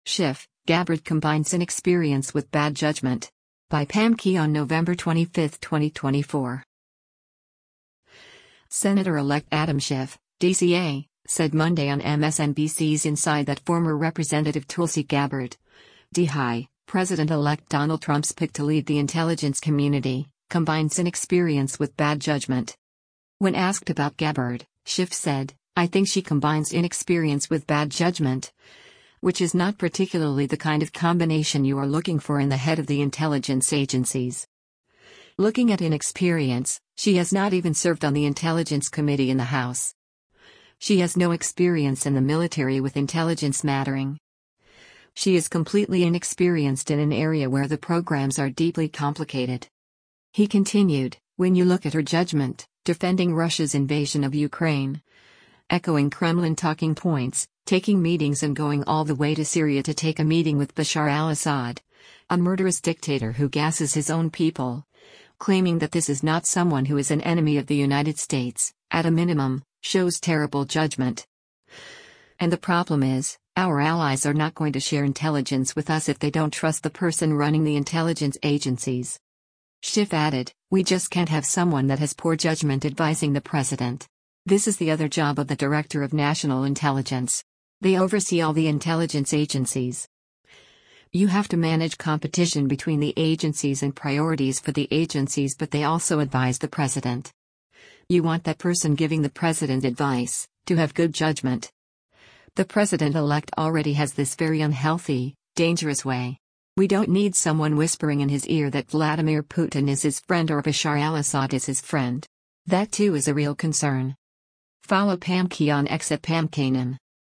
Senator-elect Adam Schiff (D-CA) said Monday on MSNBC’s “Inside” that former Rep. Tulsi Gabbard (D-HI), President-elect Donald Trump’s pick to lead the intelligence community, “combines inexperience with bad judgment.”